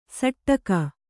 ♪ saṭṭaka